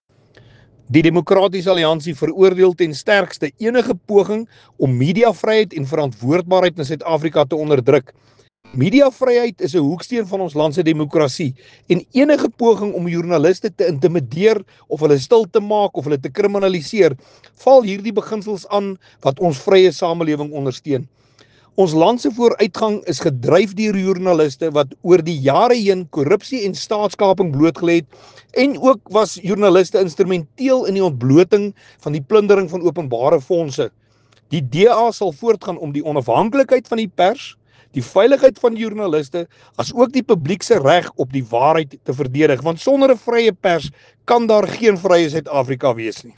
Afrikaans soundbite by Willie Aucamp MP.